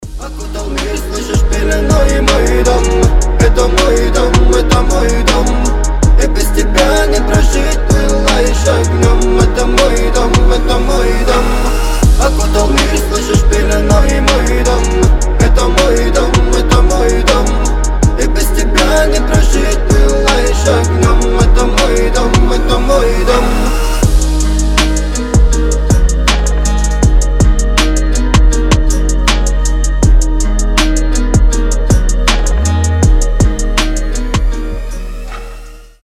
гитара
мужской голос
атмосферные
спокойные